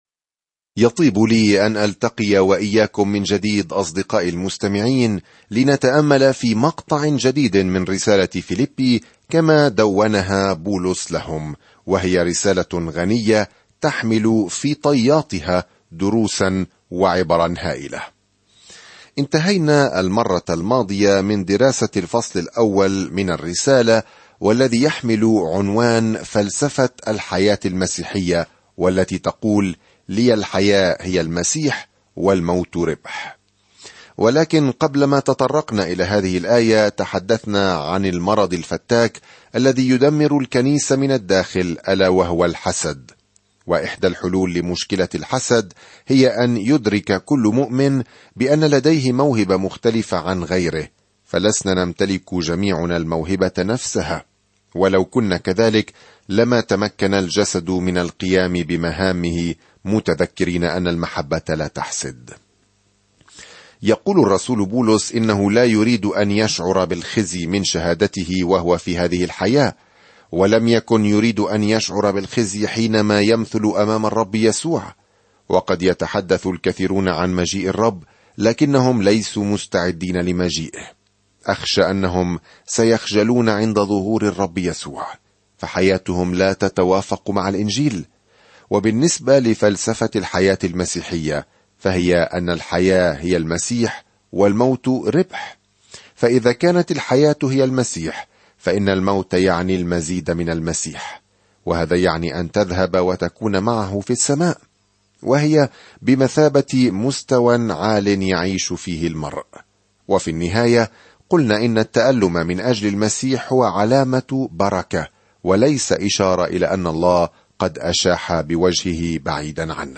الكلمة فِيلِبِّي 1:2-6 يوم 5 ابدأ هذه الخطة يوم 7 عن هذه الخطة إن رسالة "الشكر" هذه الموجهة إلى أهل فيلبي تمنحهم منظورًا بهيجًا للأوقات الصعبة التي يعيشونها وتشجعهم على اجتيازها بتواضع معًا. سافر يوميًا عبر رسالة فيلبي وأنت تستمع إلى الدراسة الصوتية وتقرأ آيات مختارة من كلمة الله.